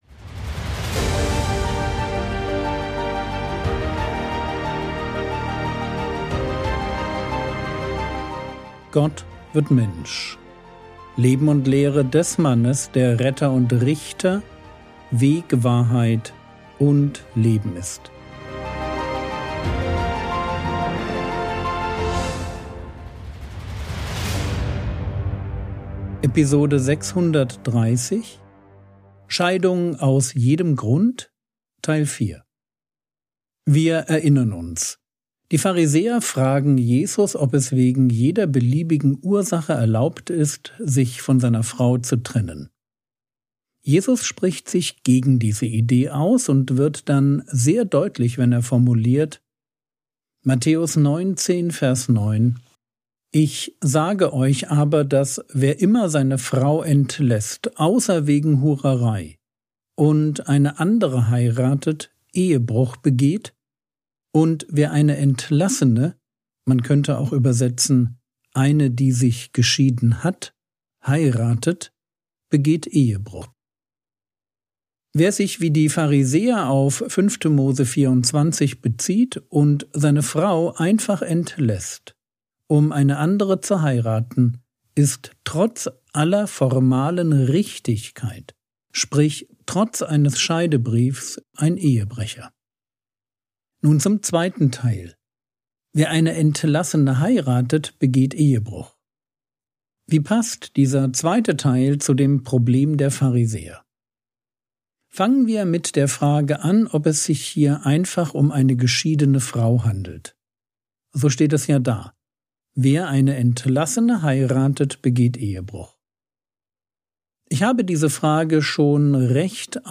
Episode 630 | Jesu Leben und Lehre ~ Frogwords Mini-Predigt Podcast